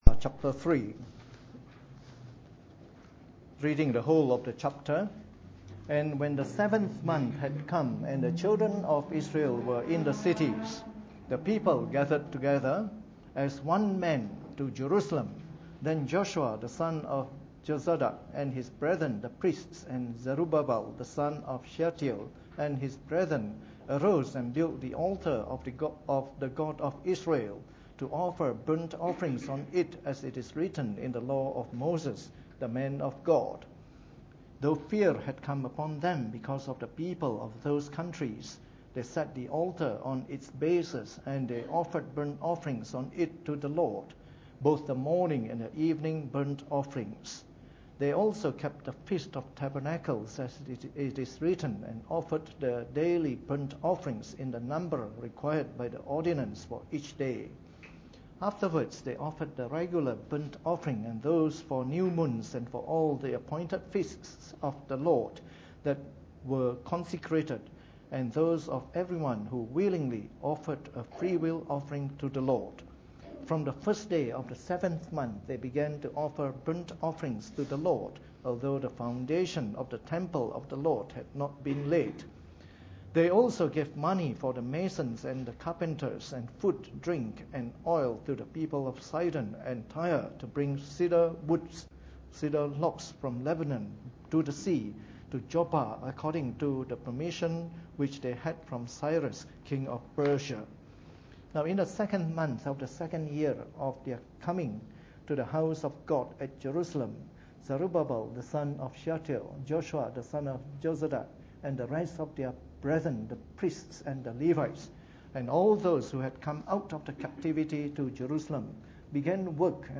Preached on the 5th of February 2014 during the Bible Study, from our new series of talks on the Book of Ezra.